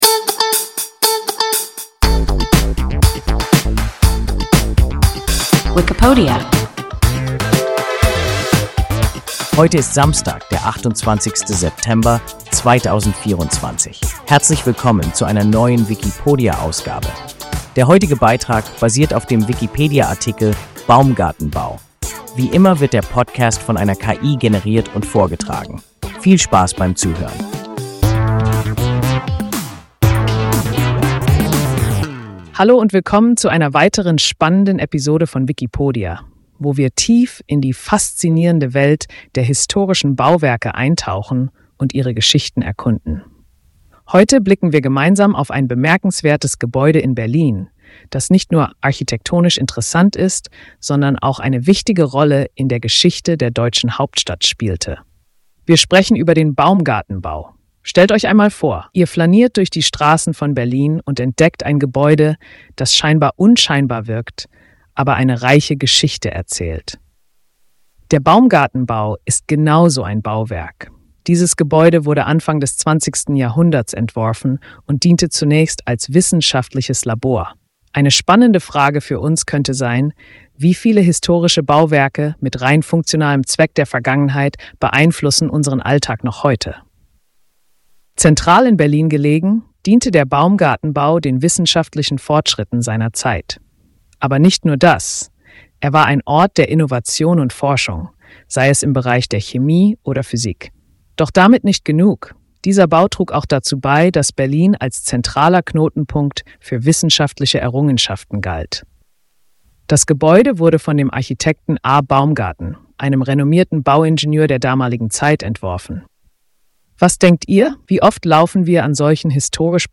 Baumgarten-Bau – WIKIPODIA – ein KI Podcast